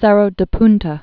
(sĕrō də pntə, -tä)